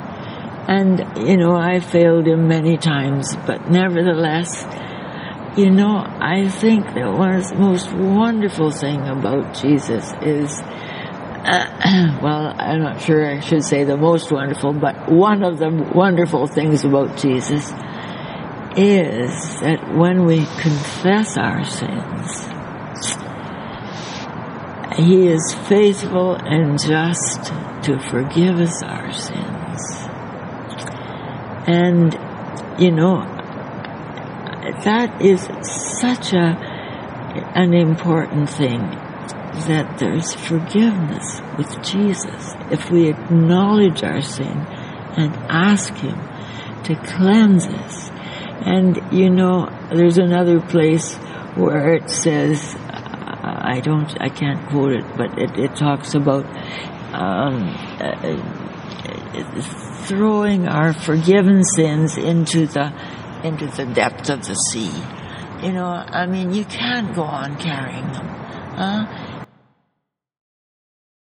We sat on a picnic bench in a clearing. Save for the noise of the odd siren and the rumble of a transit train, it was a country setting in the middle of a big city.